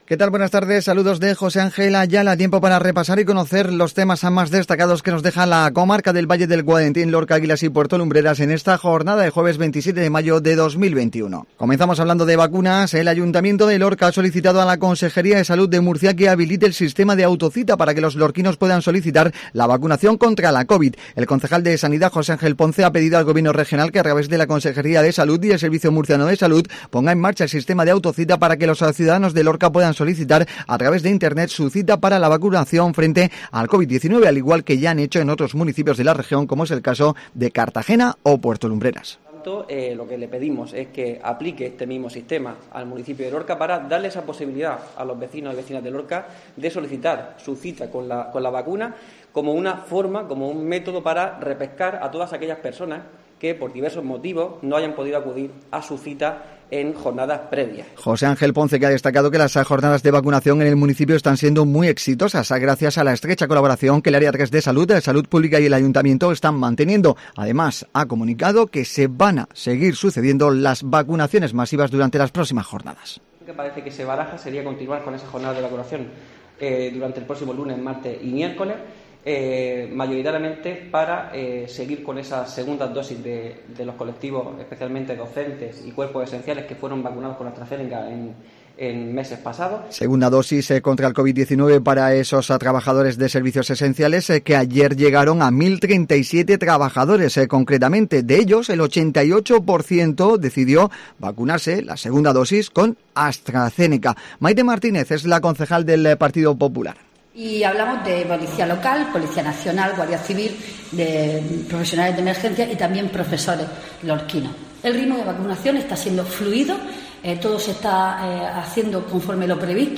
INFORMATIVO JUEVES MEDIODÍA